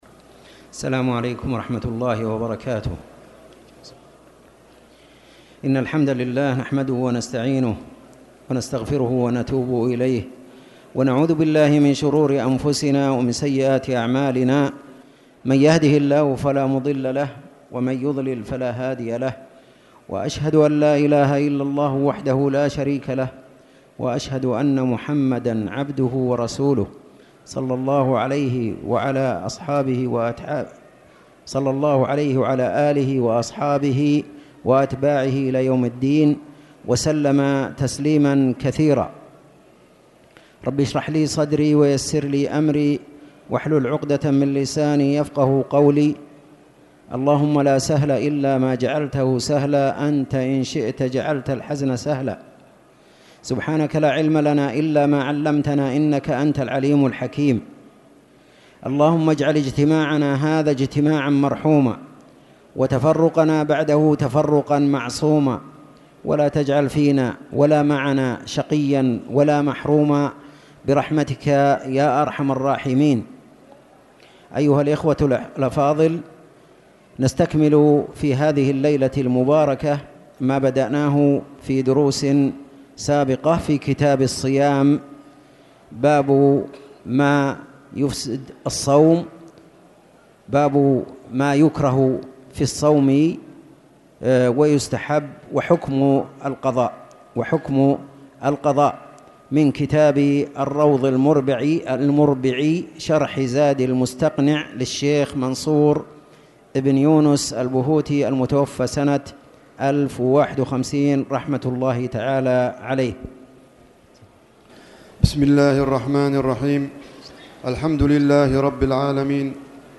تاريخ النشر ١٩ ربيع الثاني ١٤٣٨ هـ المكان: المسجد الحرام الشيخ